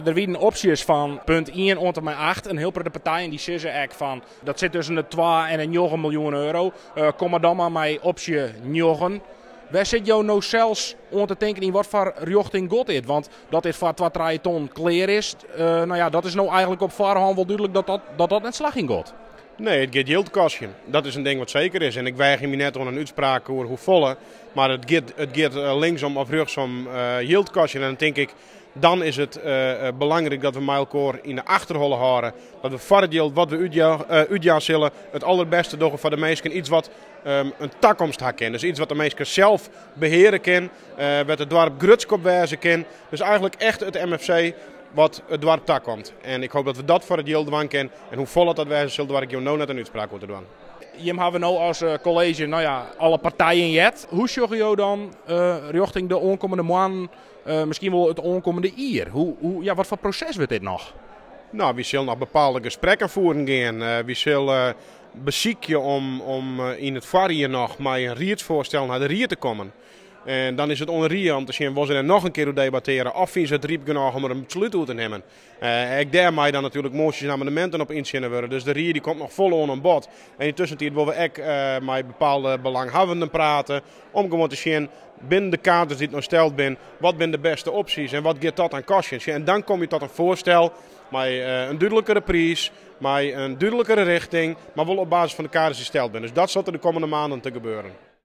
KOLLUM/BURDAARD – In een bomvolle raadszaal, waar donderdagavond tientallen Burdaarders zich hadden verzameld, werd het eerste oriënterende debat over MFC It Spektrum gevoerd.
Aant Jelle Soepboer, wethouder Noardeast-Fryslân
ST-ST-2-Aant-Jelle-Soepboer-oer-MFC-Spektrum-BdJ-1401.wav